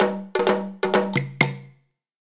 Course intro music